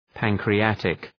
Προφορά
{,pæŋkrı’ætık}